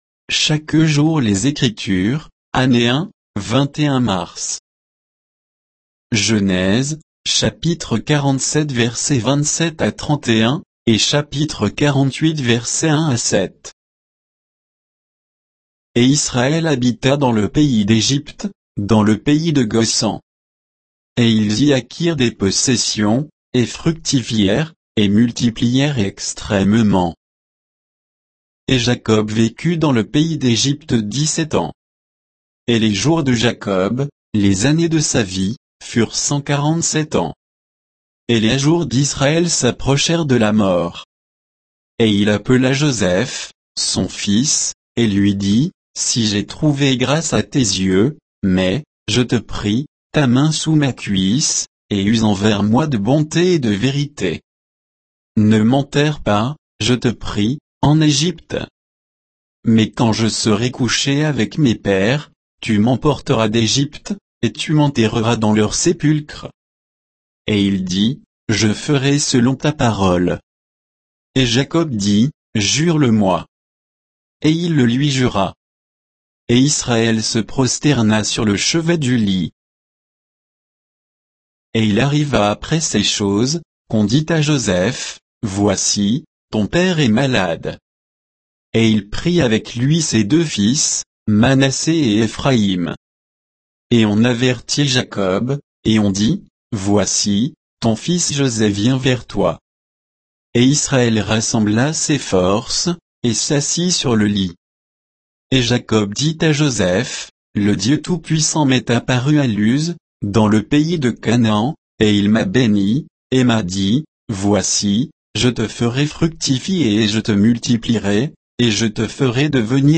Méditation quoditienne de Chaque jour les Écritures sur Genèse 47